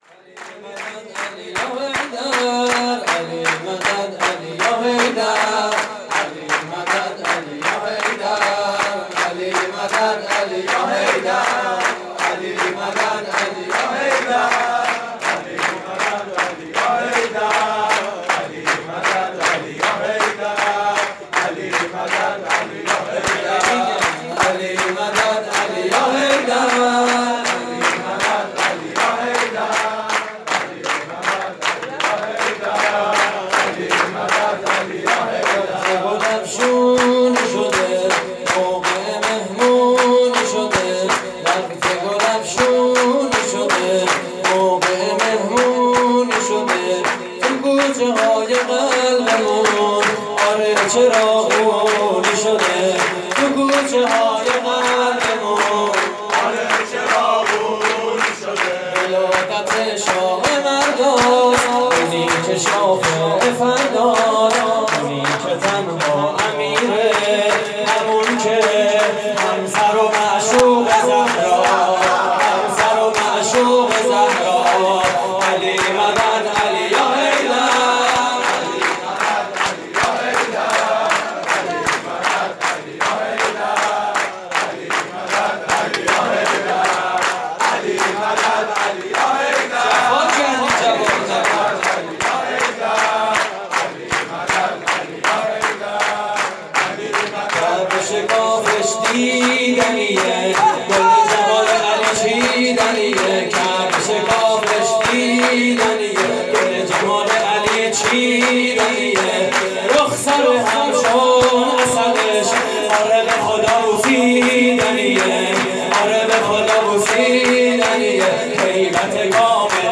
شور: صاحب ذوالفقار اومد
مراسم جشن ولادت حضرت امیر (ع)